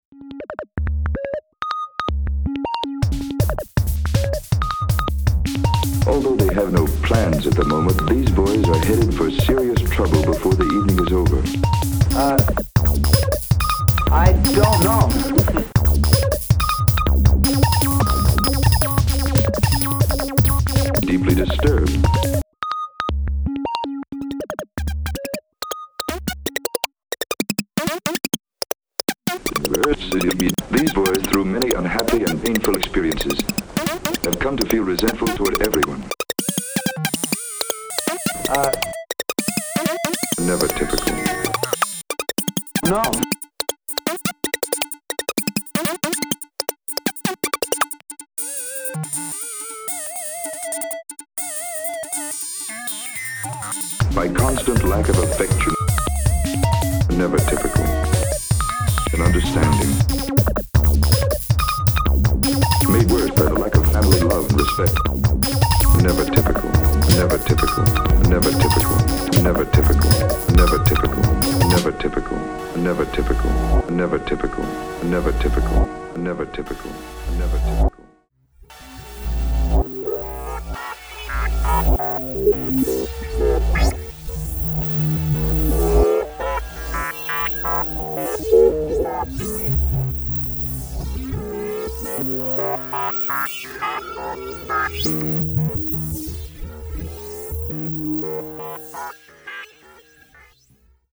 Vocals, Art Direction, Band
Synthesizer, L.S.I., Band
Keyboards, Uillean Pipes, Band